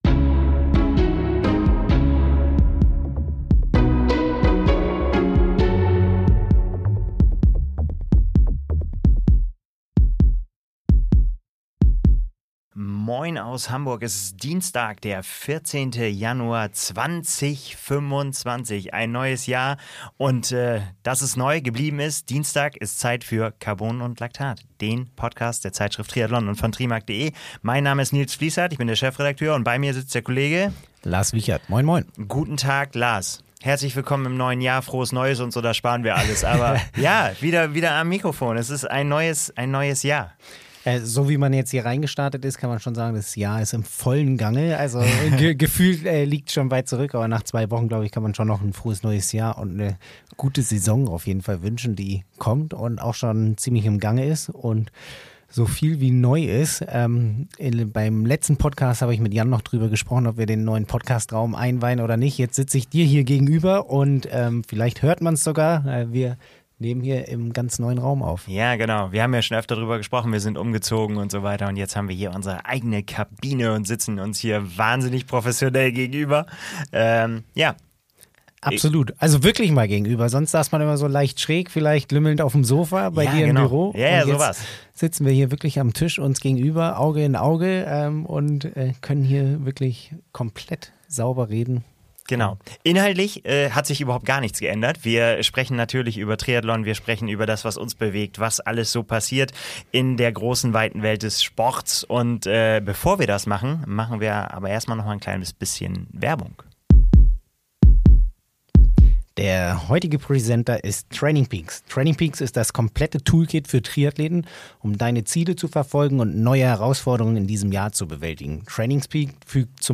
Zwei Experten aus der Redaktion sprechen über das aktuelle Triathlongeschehen.
Eine Persönlichkeit aus dem Triathlonsport im ausführlichen Gespräch.